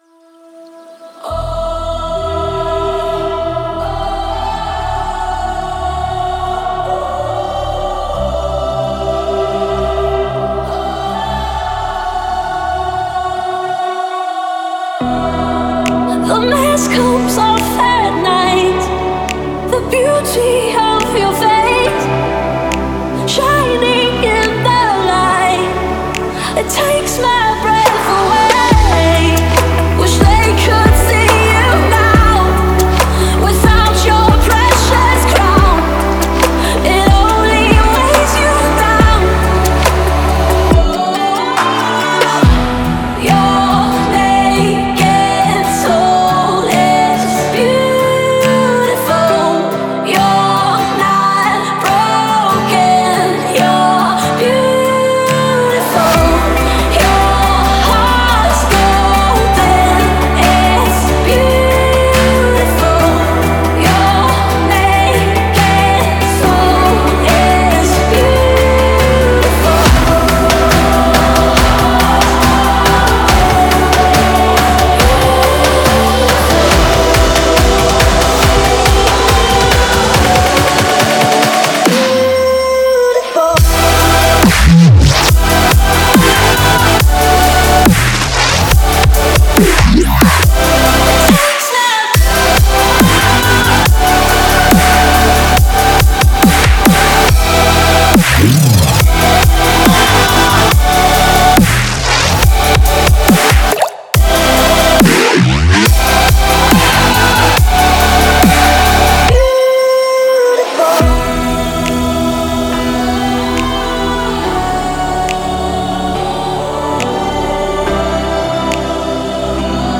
это трек в жанре EDM